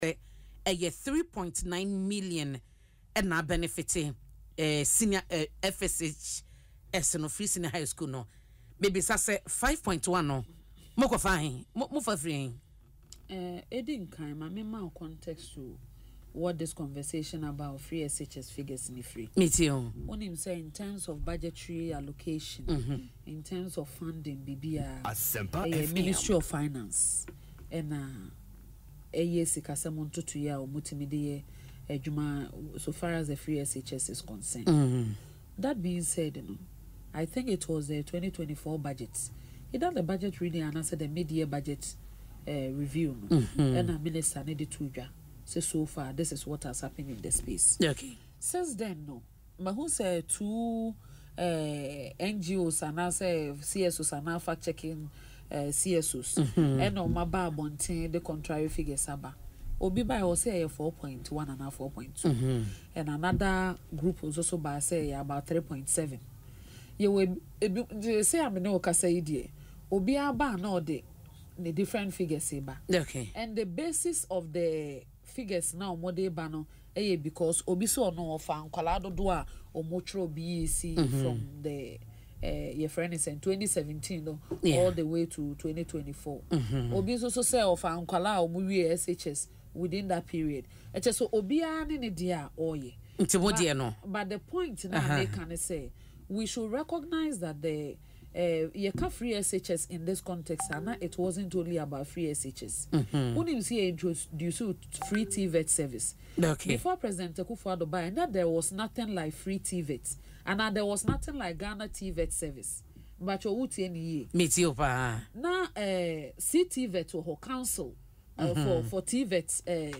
In an interview on Asempa FM Ekosii Sen, the legal practitioner stated that it included the free Technical and Vocational Education and Training (TVET), which also increased enrolment.